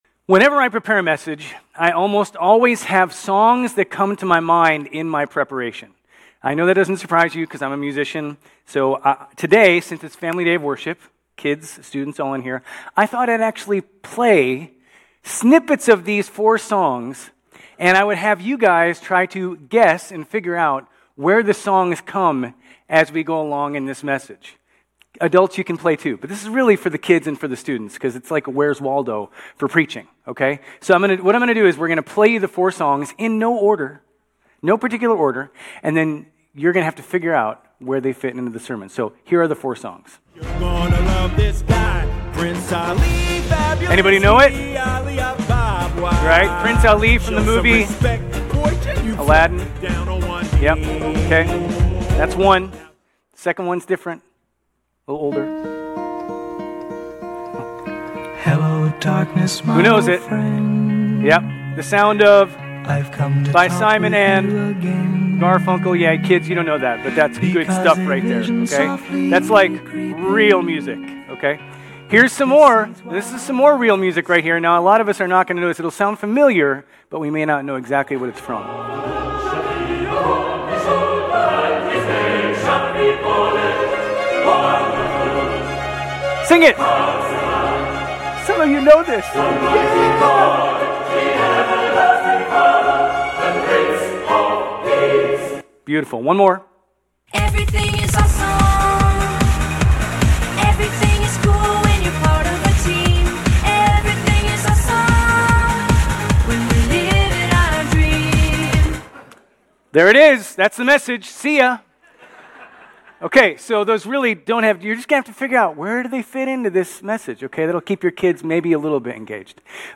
Grace Community Church Old Jacksonville Campus Sermons 11_30 Old Jacksonville Campus Dec 01 2025 | 00:35:49 1x 00:00 / 00:35:49 Subscribe Share RSS Feed Share Link Embed